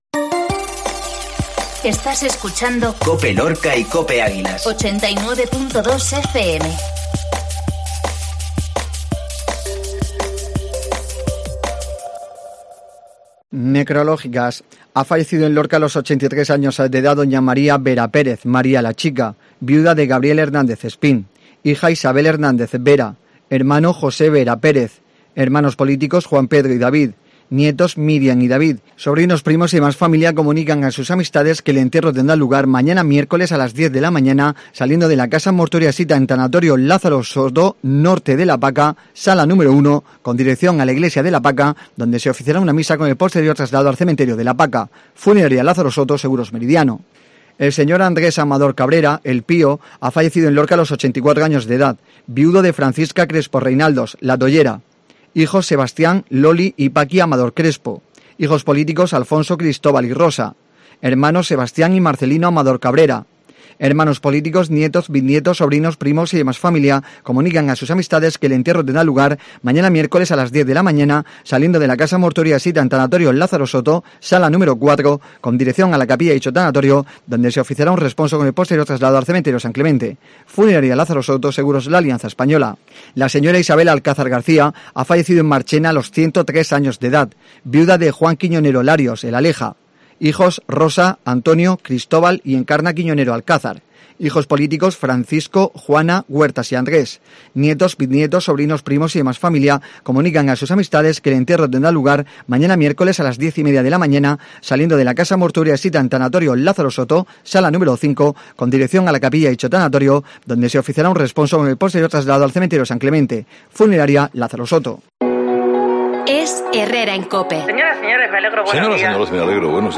INFORMATIVO MEDIODÍA MARTES